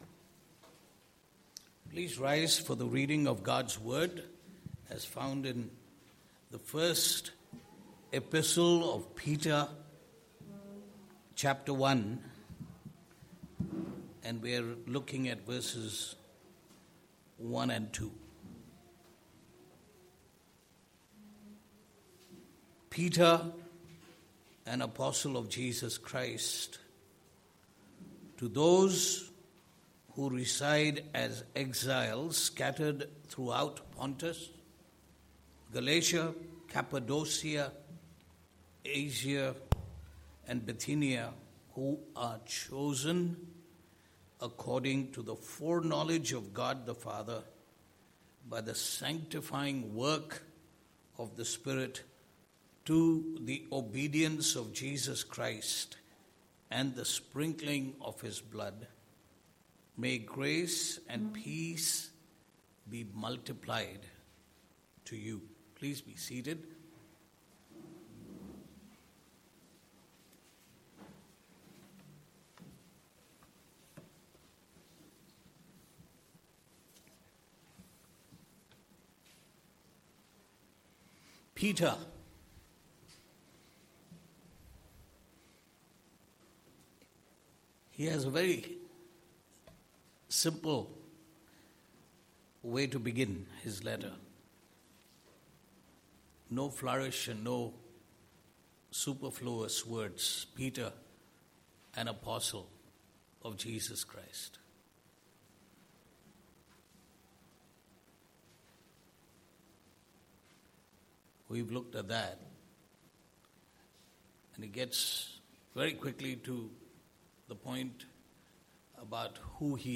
2 Service Type: Sunday Morning « The Glory of Election